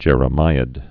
(jĕrə-mīəd)